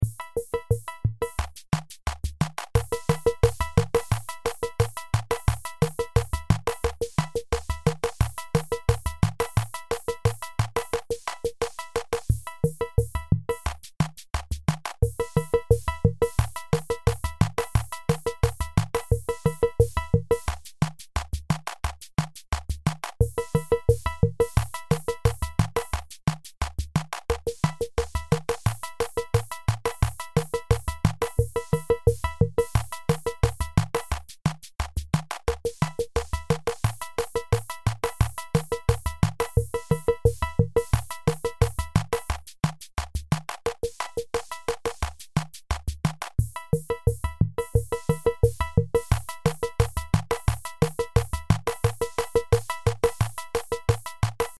cheesy funky pop beat machine 3000.mp3